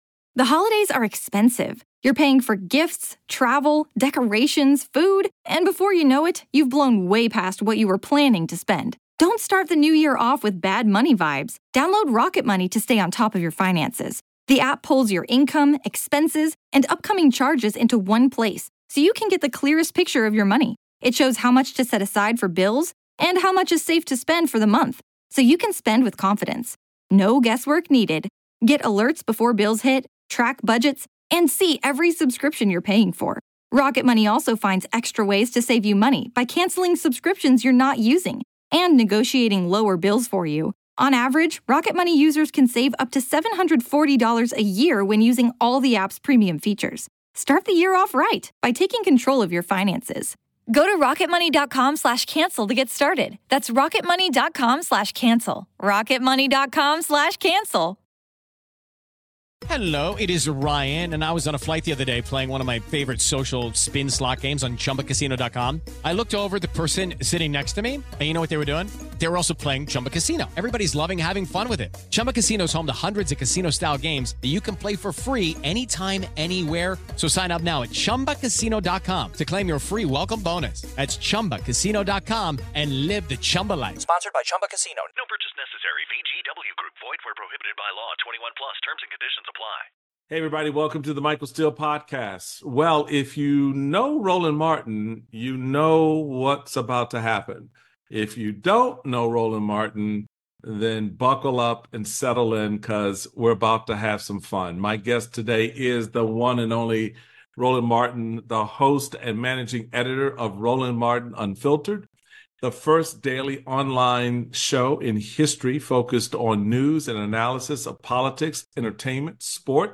Michael Steele speaks with Roland Martin, commentator and host of Roland Martin Unfiltered. The pair discuss the changing landscape of media, the future of broadcasting and the importance of understanding the business and adapting to new technologies. They also discuss the Trump trial, the relationship between Black men and Trump and whether Black people identify with him due to shared experiences with the criminal justice system.